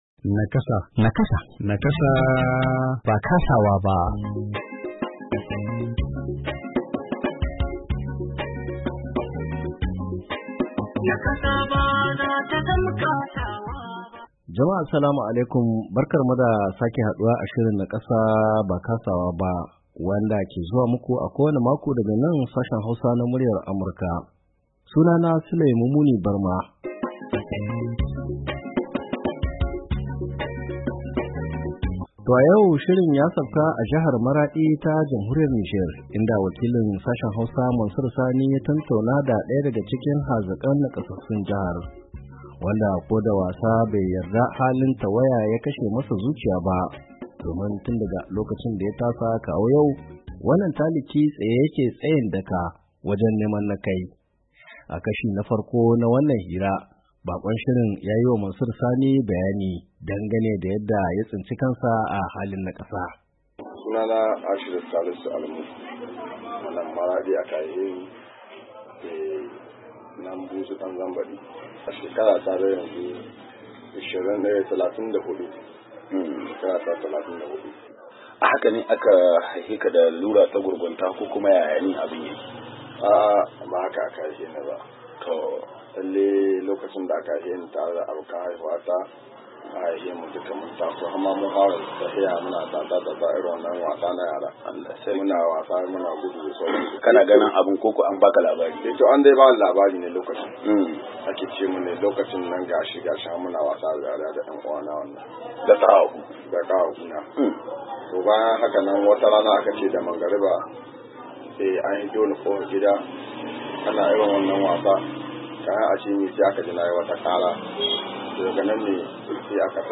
NIAMEY, NIGER - A cikin shirin na wannan makon, mun sauka a jihar Maradi ta Jamhuriyar Nijar inda muka tattauna da daya daga cikin hazikan nakasassun Jihar, wanda ko da wasa bai yarda halin da yake ciki ya kashe masa zuciya ba domin tun daga lokacin da ya taso kawo yanzu tsaye yake, tsayin daka wajen neman na kai.